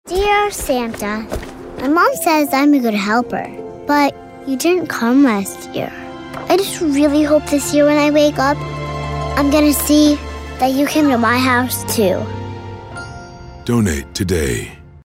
Toys for Tots Digital Media Library (Radio PSAs)